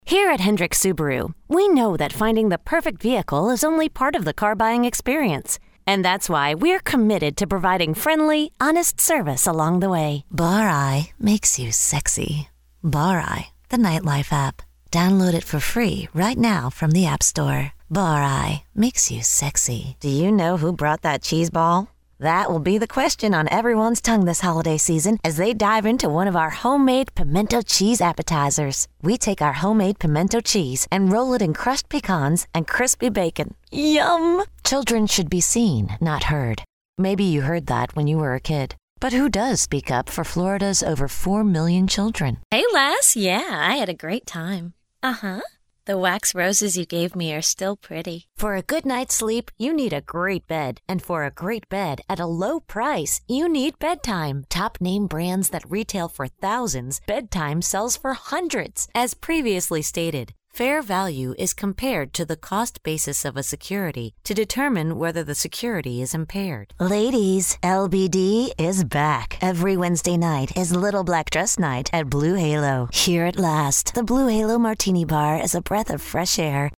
Female
English (North American)
Yng Adult (18-29), Adult (30-50)
low mid and high range, versatile, friendly, Mom, sexy, raspy, relatable, professional